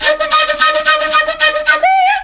FLUT 04.AI.wav